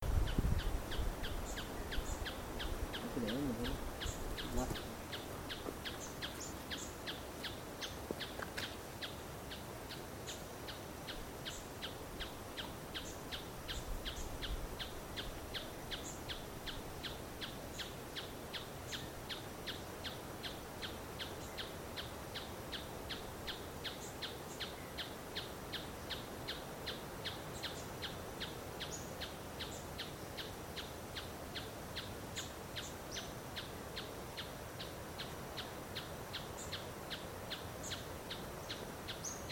Tapaculo-ferreirinho (Scytalopus pachecoi)
Localidade ou área protegida: Parque Provincial Caá Yarí
Condição: Selvagem
Certeza: Gravado Vocal